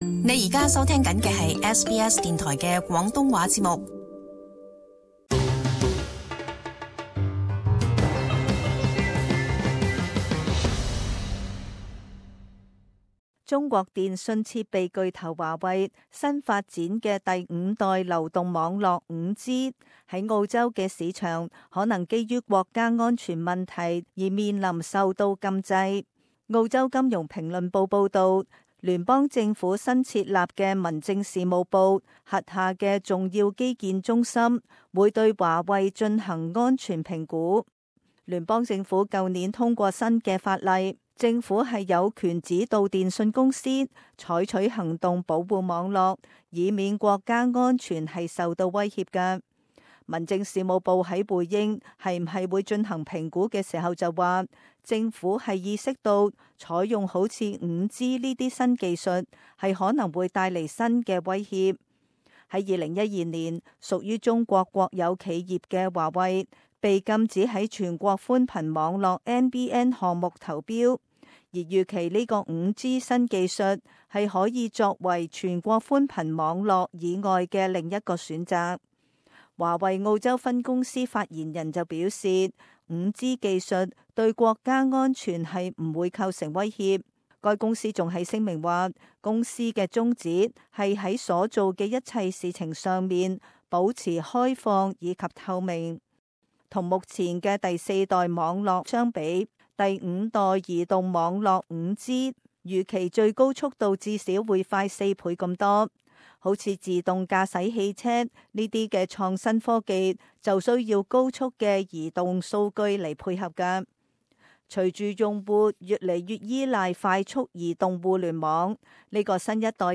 【時事報導】5G流動網絡隱憂